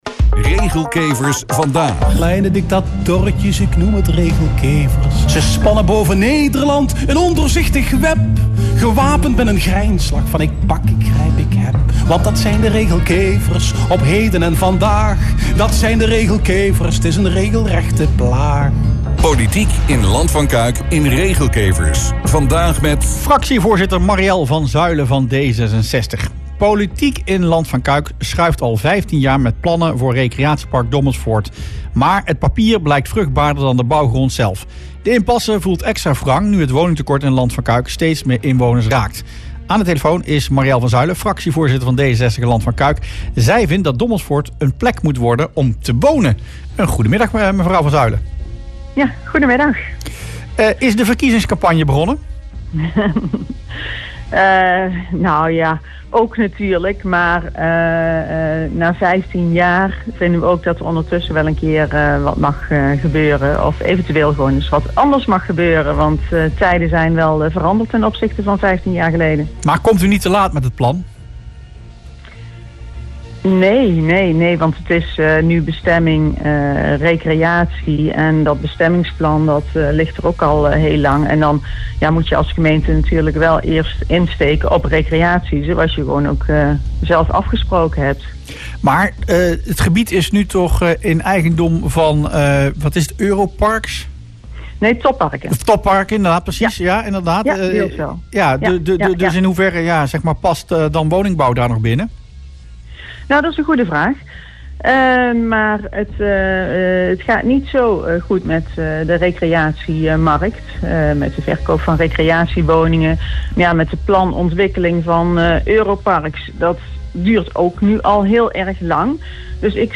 BOXMEER – Dommelsvoort in Cuijk zou in plaats van een recreatiepark een woonwijk moeten worden. Dit voorstel deed D66-fractievoorzitter Mariëlle van Zuilen in het radioprogramma Rustplaats Lokkant. Volgens haar sluit het plan voor een recreatiepark niet meer aan bij de huidige woningnood in de regio.